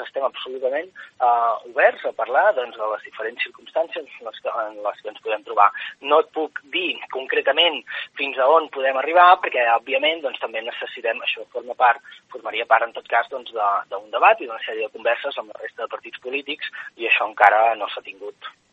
En una entrevista a Ràdio Calella TV, ha remarcat que fins ara no hi ha hagut un pronunciament clar i rotund del socialisme català en aquesta línia i lamenta que el socialisme català s’estanqui en l’equidistància reprovant d’igual manera la intervenció de les institucions catalanes i la declaració d’independència.